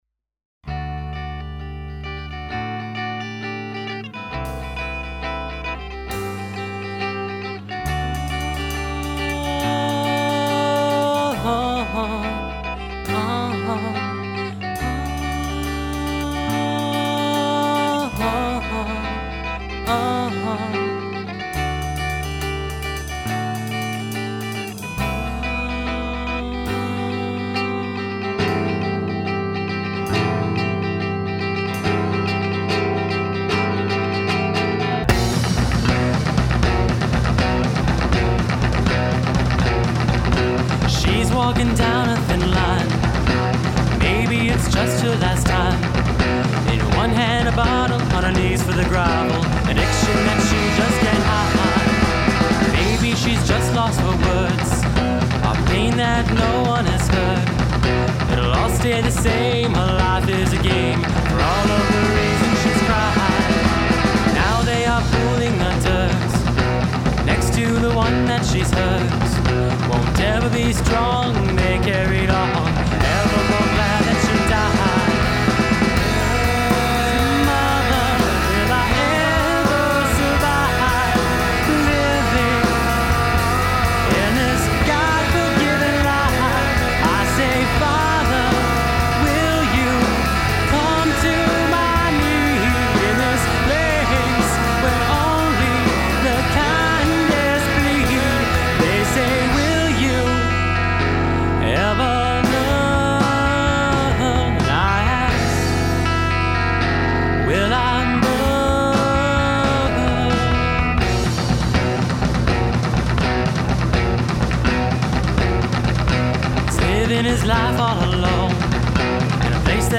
He has a great voice.